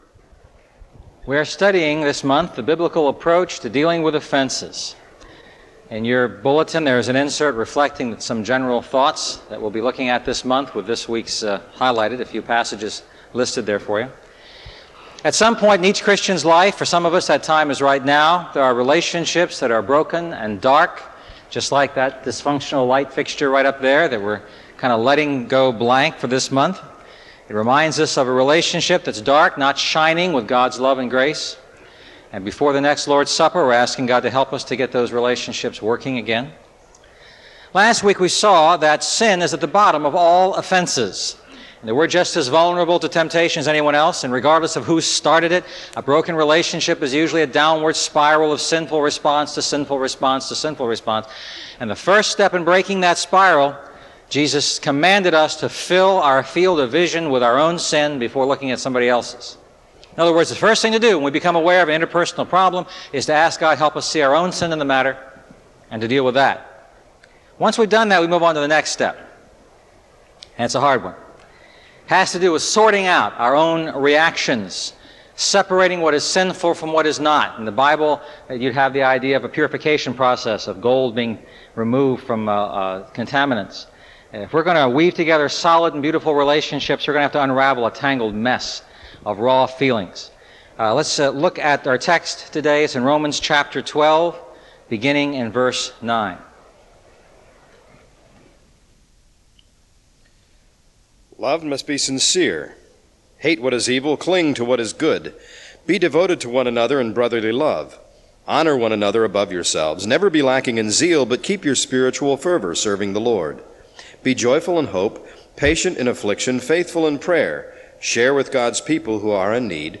Expository